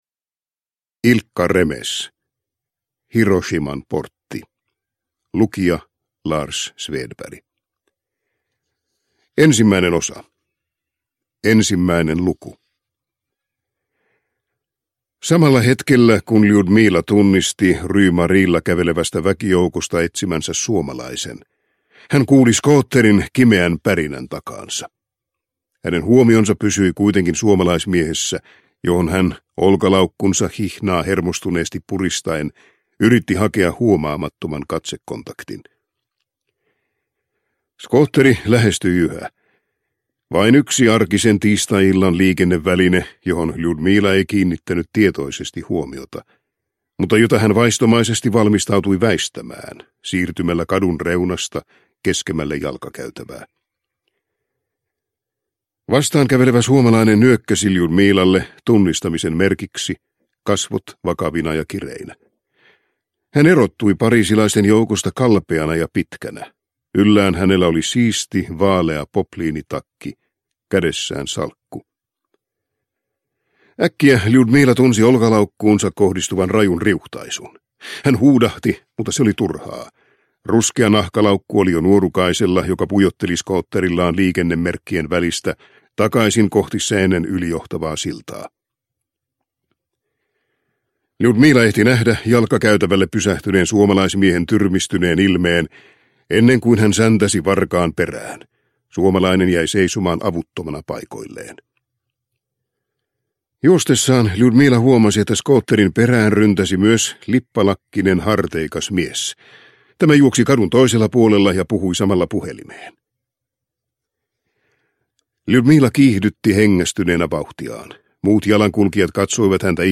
Hiroshiman portti – Ljudbok – Laddas ner